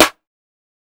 Snares (Turkish).wav